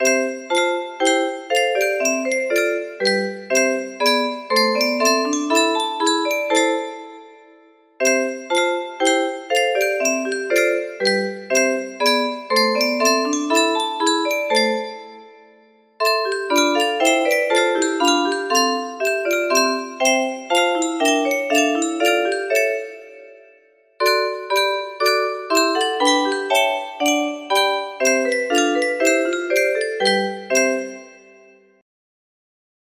O Sacred Head, Now Wounded music box melody